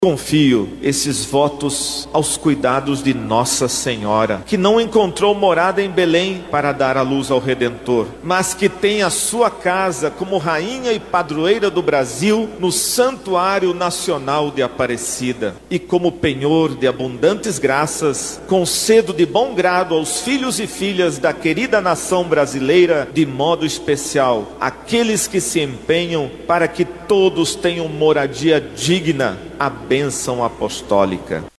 O vice-presidente da Conferência Nacional dos Bispos do Brasil (CNBB), Dom Ricardo Hoepers, cita um trecho da mensagem enviada pelo Papa a Igreja do Brasil.
Sonora-1-Dom-Ricardo-Hoepers.mp3